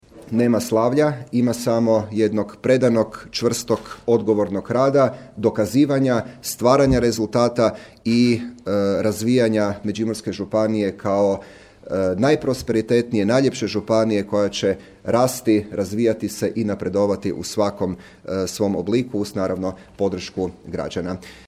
A baš kao i na svibanjskim izborima kada je također izabran s rekordnom podrškom građana, poručio da rezultat obvezuje: